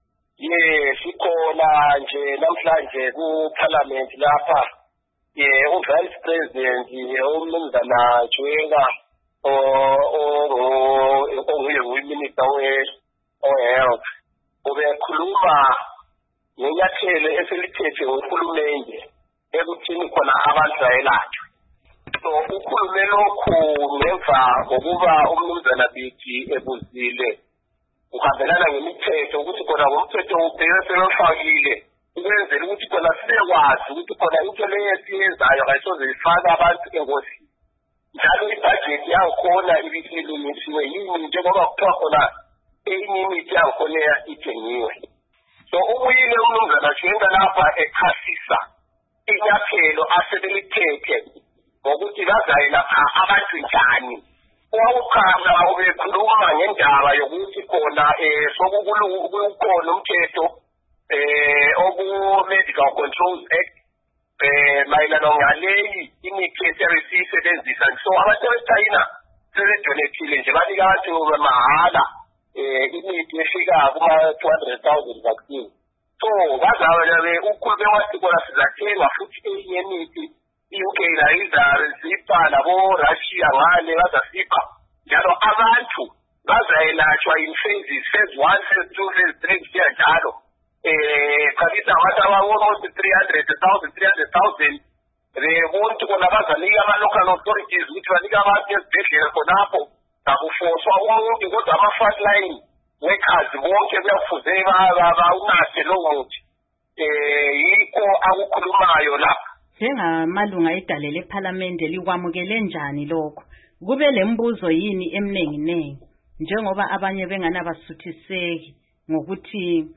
Ingxolo